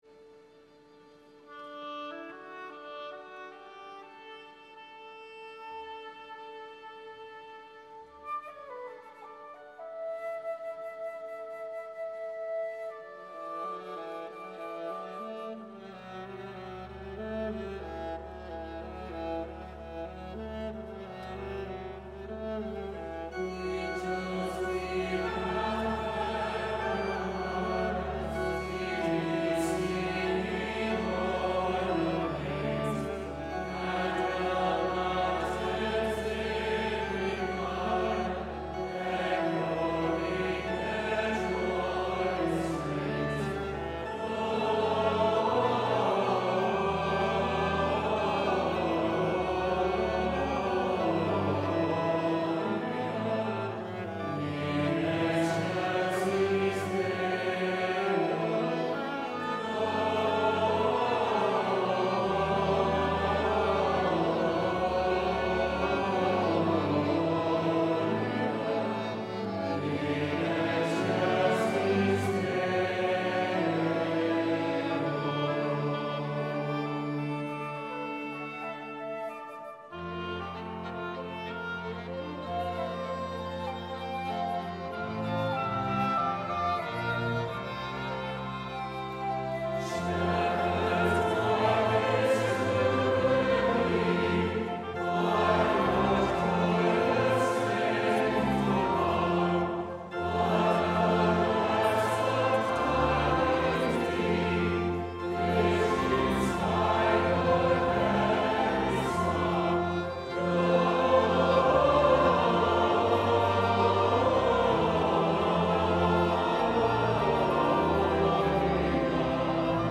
SATB Choir, Congregation, Flute, Oboe, Organ, and Strings
For BYU Devotional with Elder Neil L. Andersen
Angels-We-Have-Heard-on-High-Live-Audio-Andersen-Devo.mp3